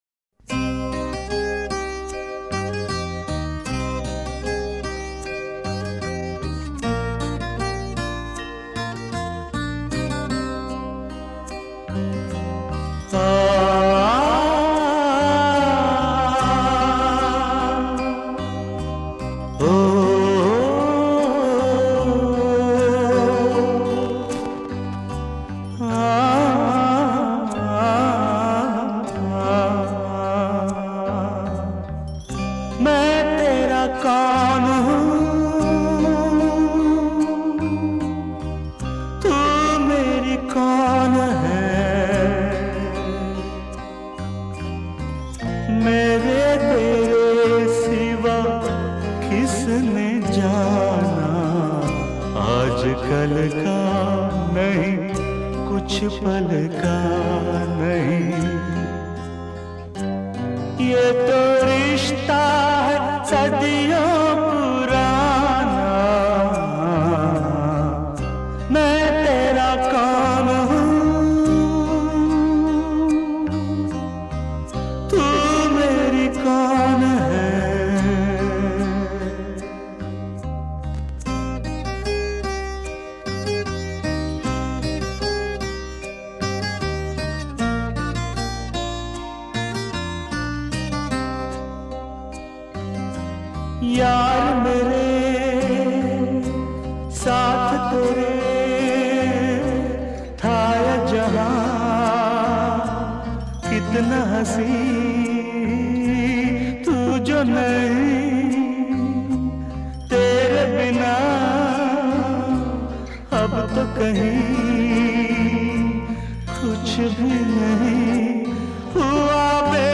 Hindi Movie